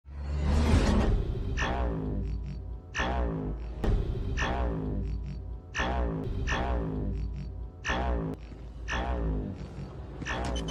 Глухие шаги, рычание, эхо пещер – все для погружения в атмосферу мифа.
Зловещий звук монстра из фильма Риддик